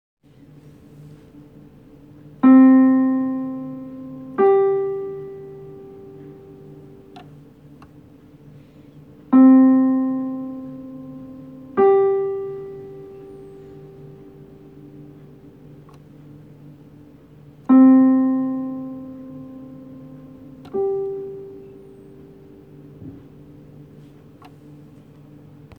interval 5th